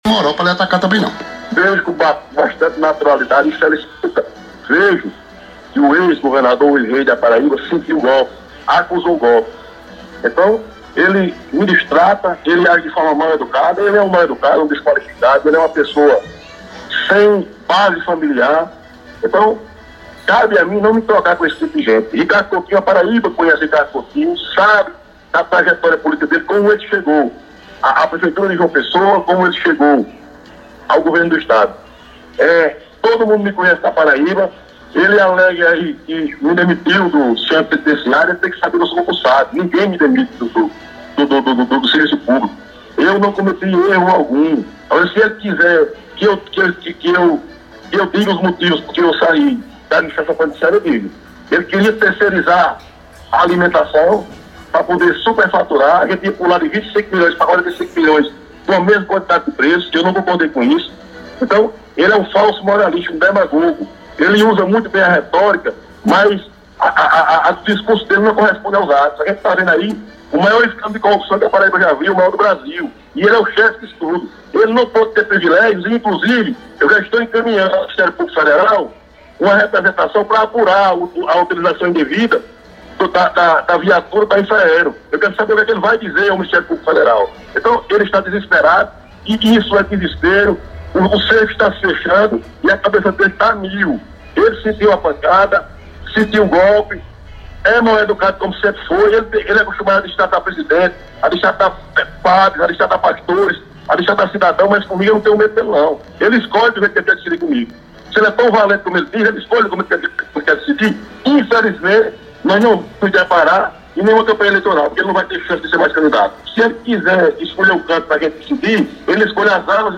Fala de Wallber no Correio Debate: